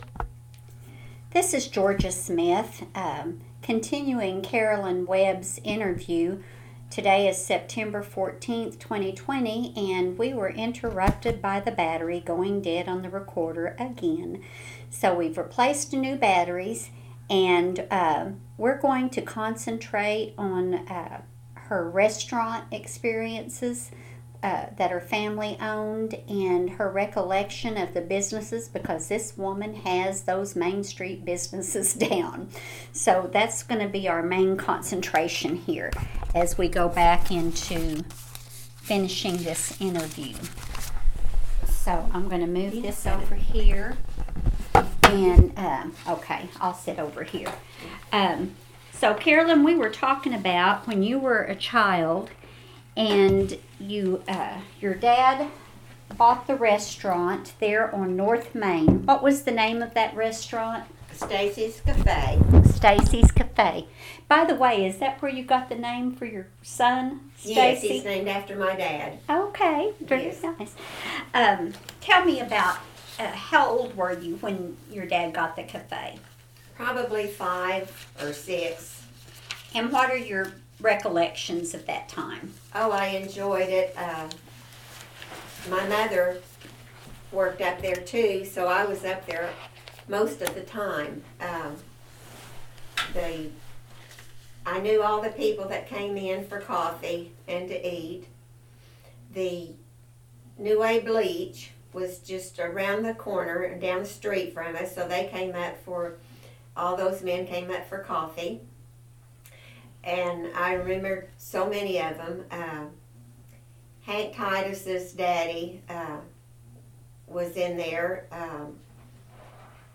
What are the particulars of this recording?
Preface: The following oral history testimony is the result of a cassette tape interview and is part of the Bristow Historical Society, Inc.'s collection of oral histories.